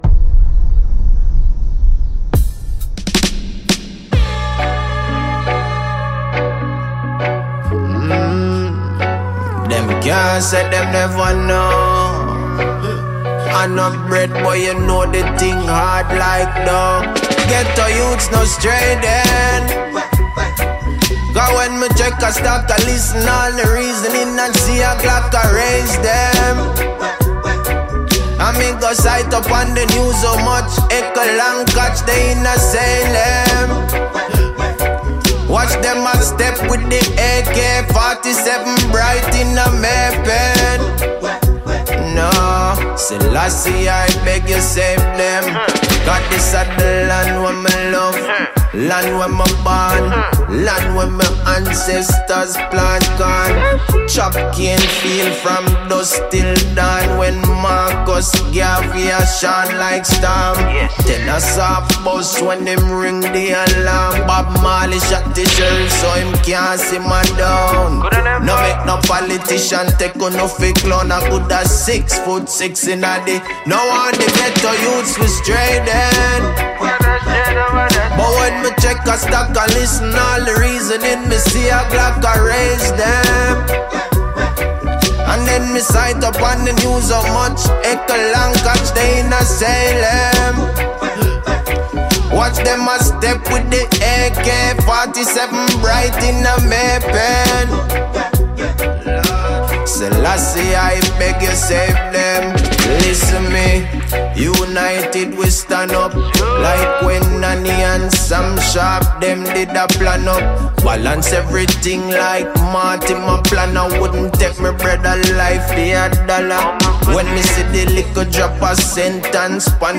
ROTOTOM SUNSPLASH 2025
🌿 Temi trattati nell’intervista: